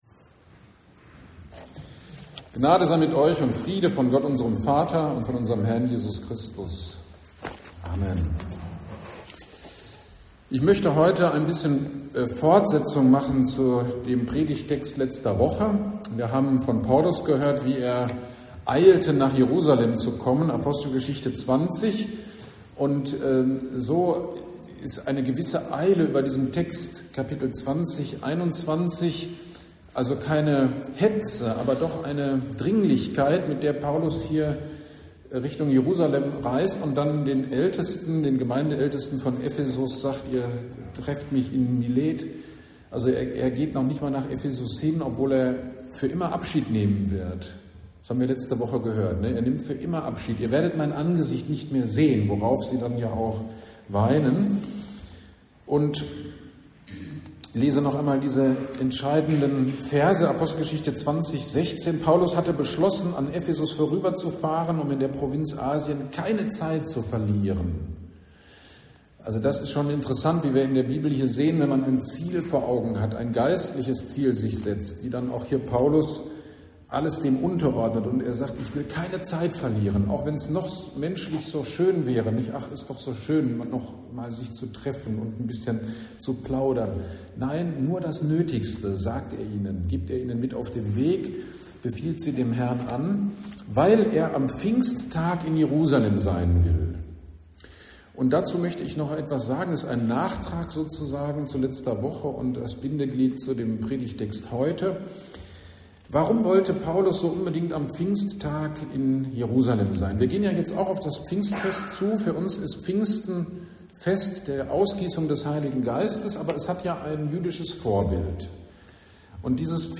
GD am 11.05.25 Predigt zu Apostelgeschichte 21, 1-14